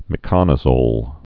(mĭ-kŏnə-zōl)